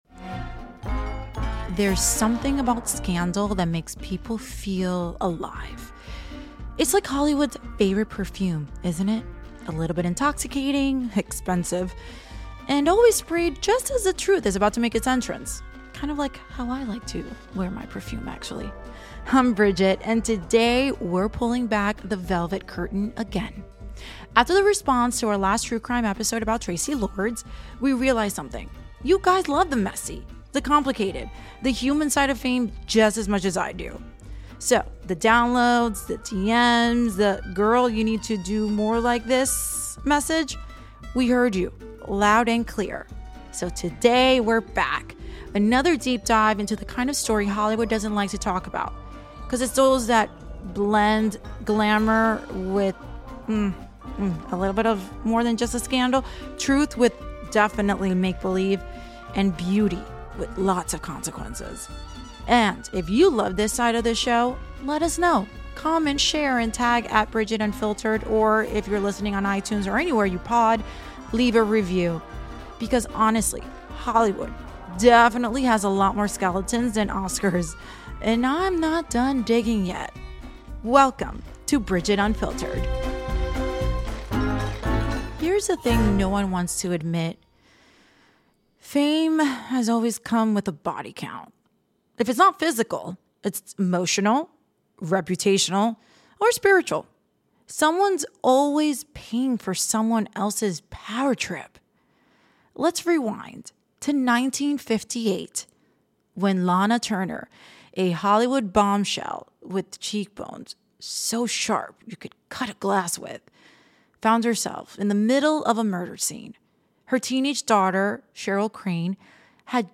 Expect raw storytelling, pop-culture parallels, and unfiltered insight into: